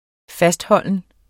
Udtale [ -ˌhʌlˀən ]